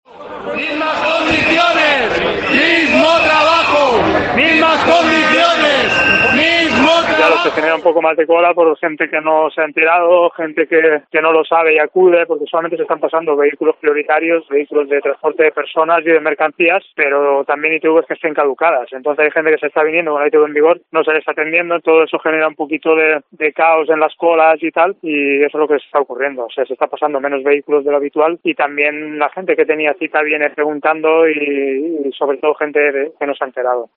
Así suena el primer día de huelga de los trabajadores de las ITV